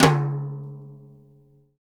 Index of /90_sSampleCDs/Roland - Rhythm Section/TOM_Real Toms 1/TOM_Dry Toms 1
TOM HITOM 0K.wav